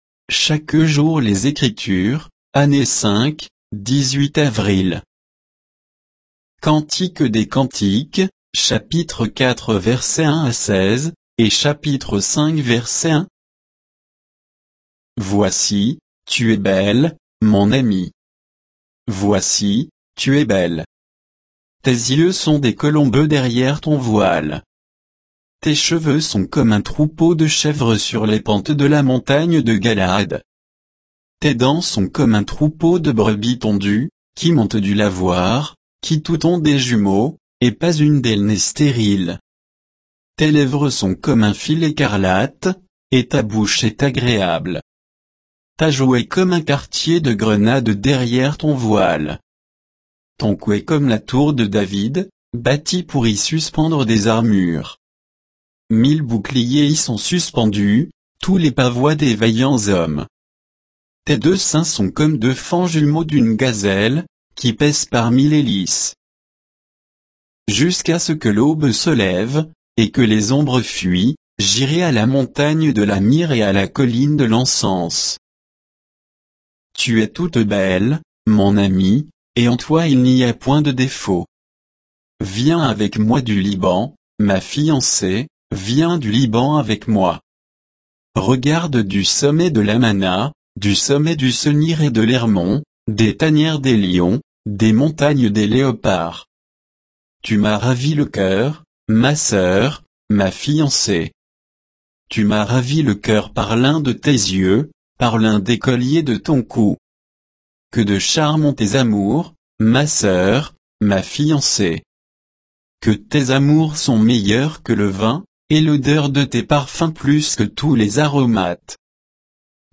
Méditation quoditienne de Chaque jour les Écritures sur Cantique des cantiques 4, 1 à 5, 1